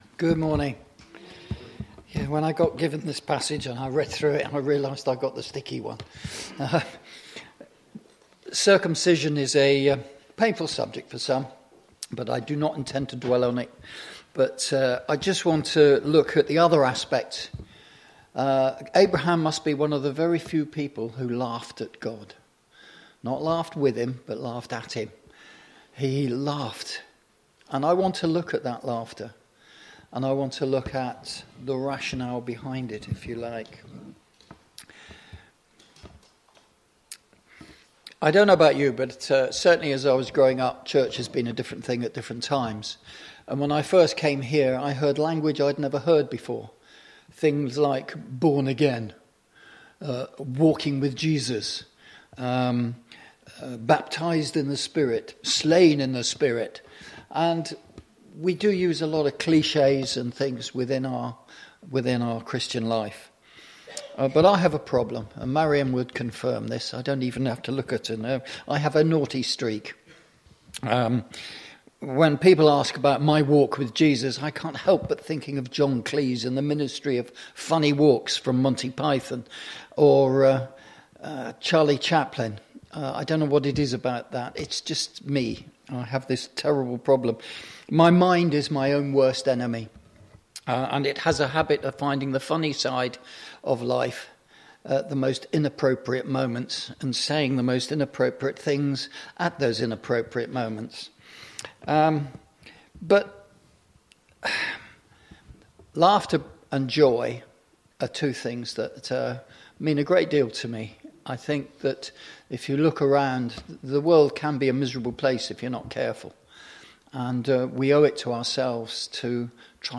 Recording-Sunday-Service-02.03.2025.mp3